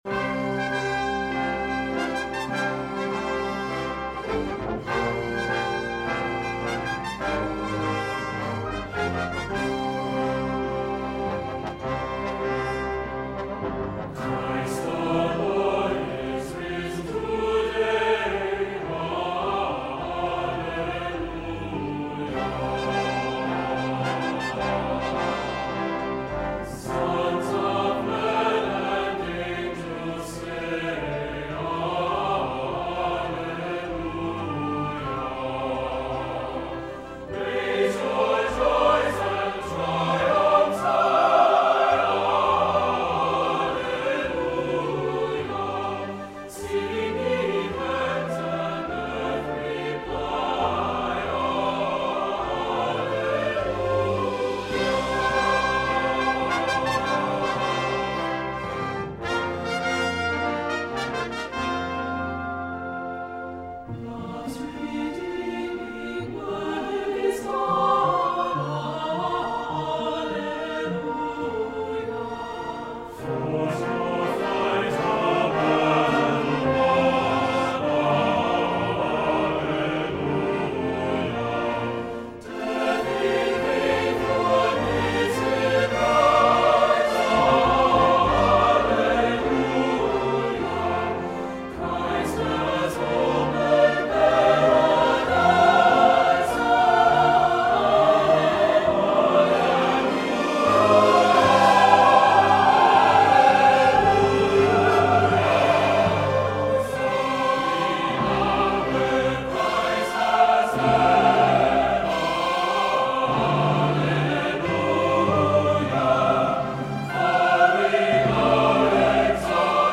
Voicing: Full Orch,